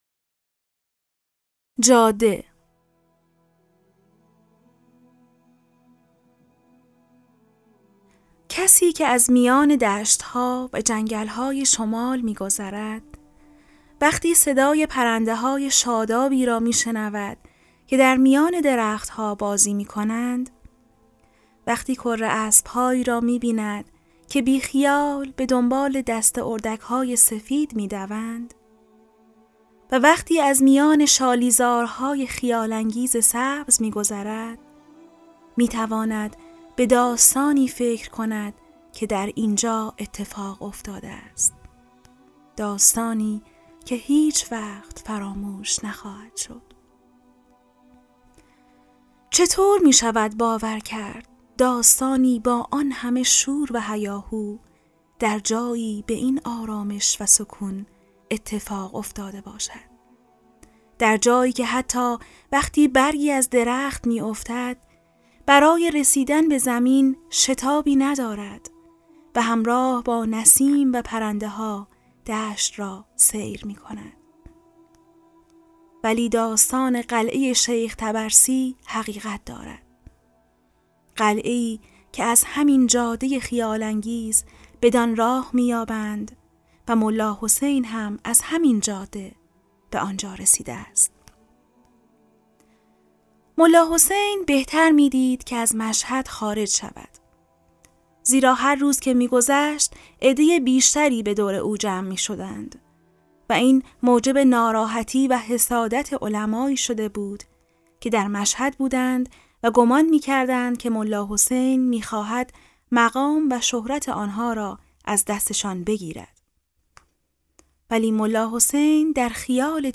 کتاب صوتی سالهای سبز | تعالیم و عقاید آئین بهائی